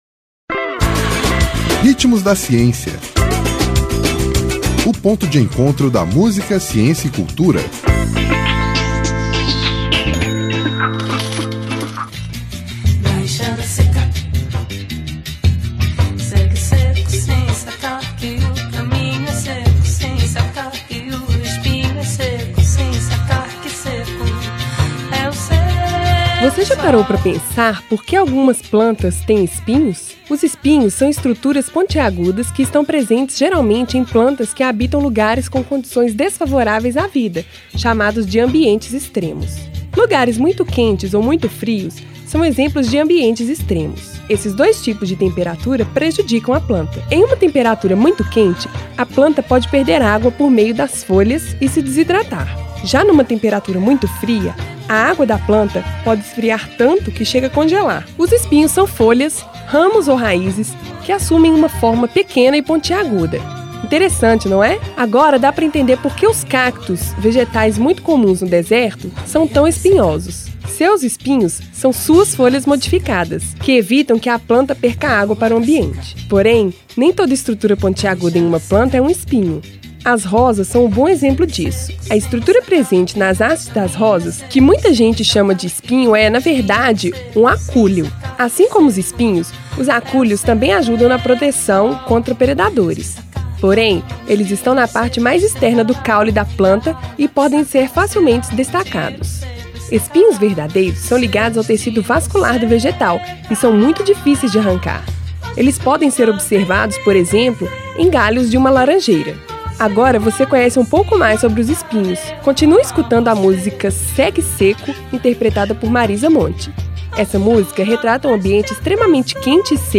Nome da música: Segue o Seco
Intérprete: Marisa Monte